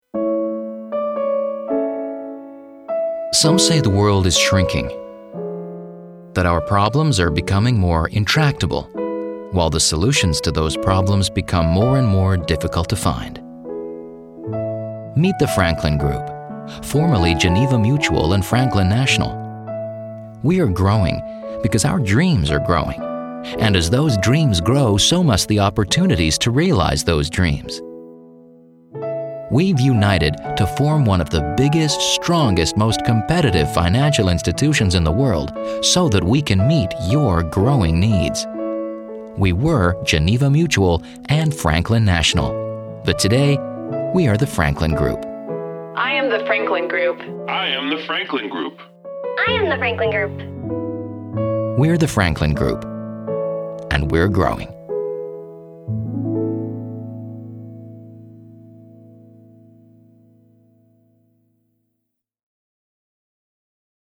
Radio Commercials
(Corporate American Accent)